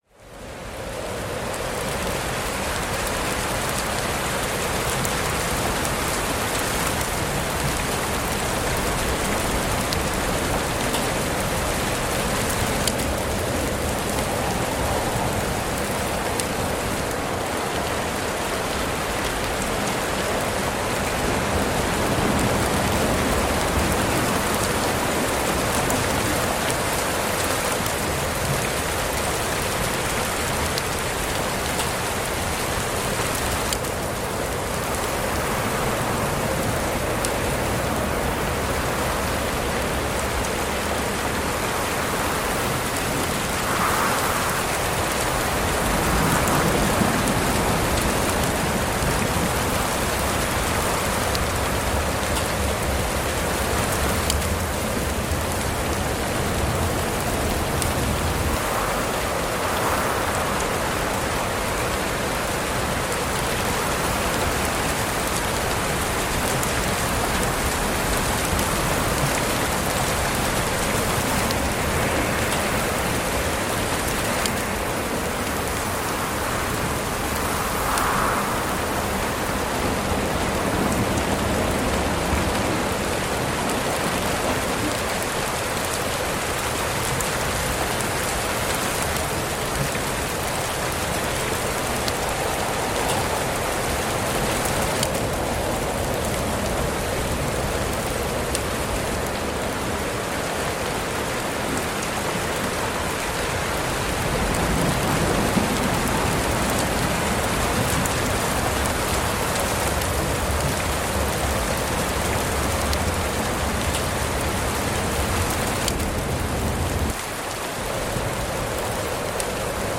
Pluie résonnante en environnement rocheux avec circulation d’air naturelle